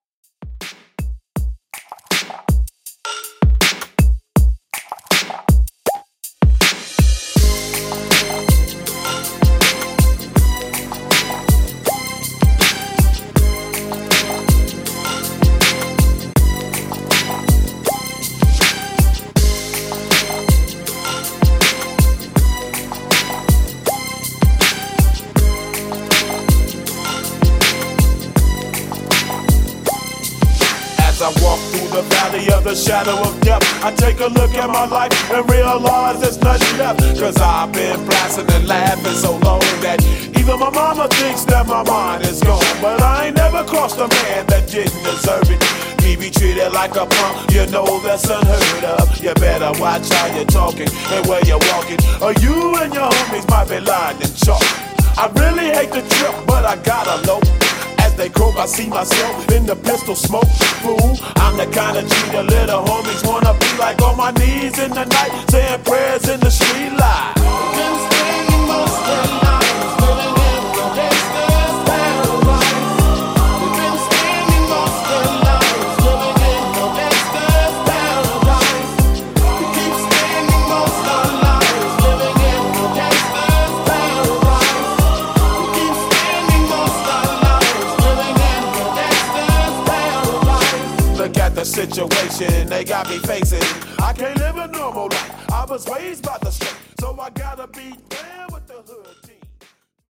Remix Redrum)Date Added